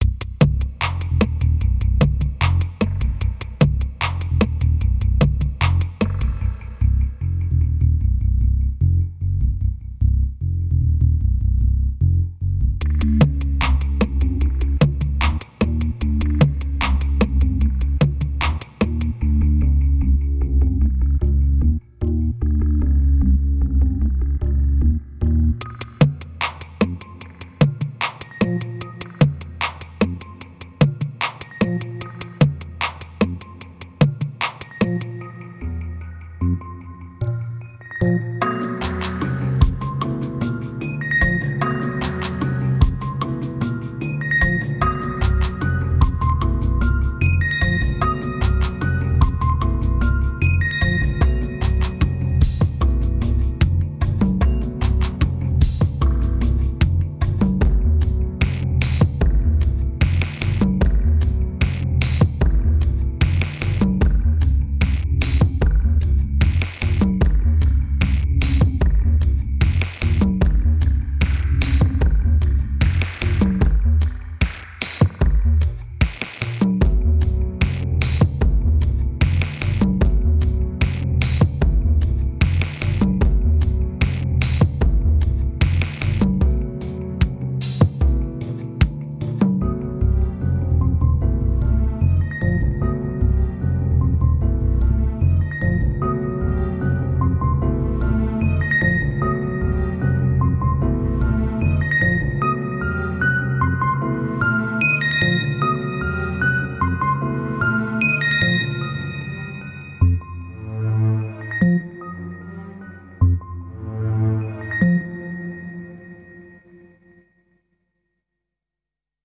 2475📈 - 0%🤔 - 75BPM🔊 - 2009-09-03📅 - -173🌟